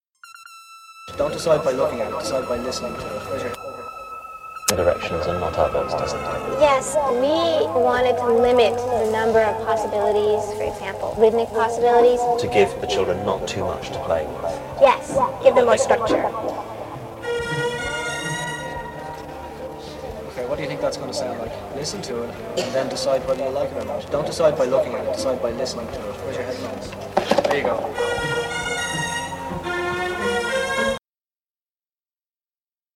Ambient, Downtempo, Dub
Trip hop